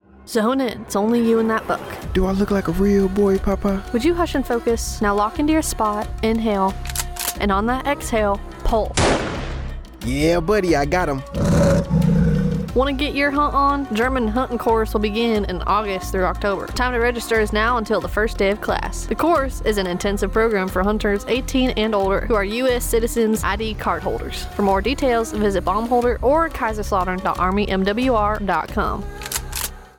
Radio Spot - German Hunting Courses AFN Kaiserslautern